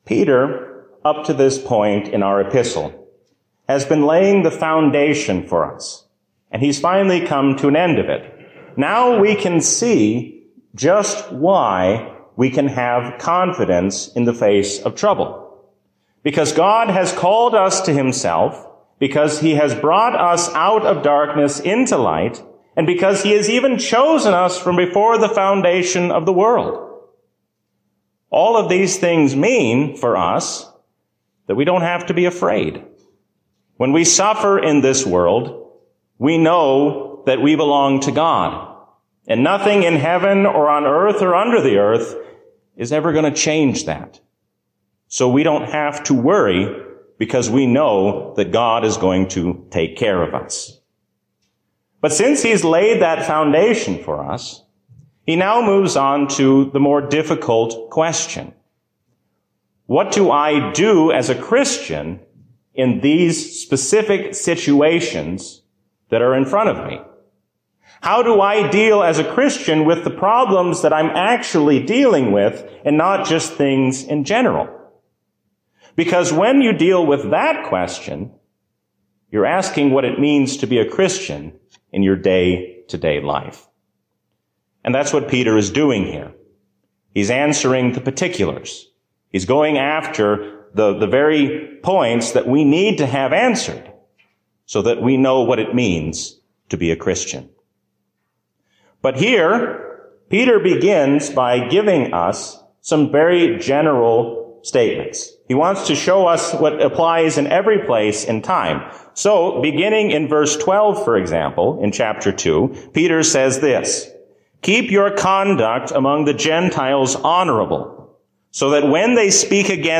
A sermon from the season "Easter 2022." Let us be Christians not only in our words, but also in what we do.